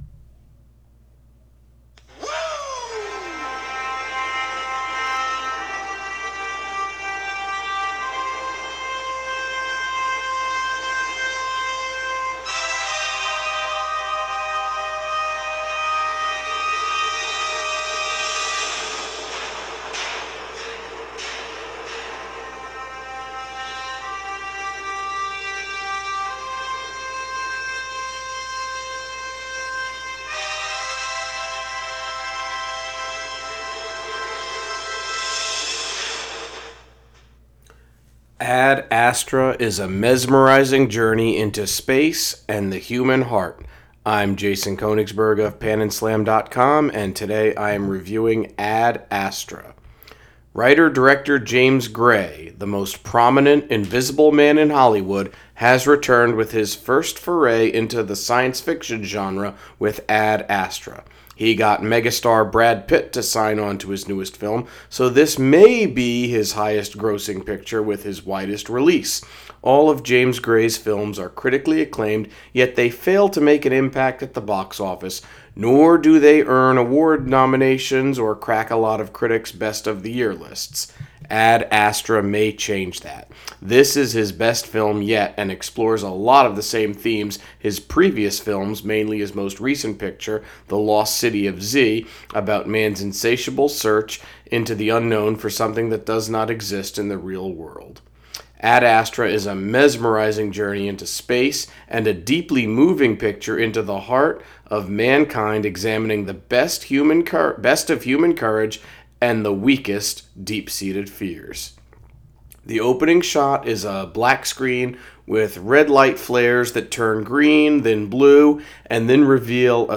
Movie Review: Ad Astra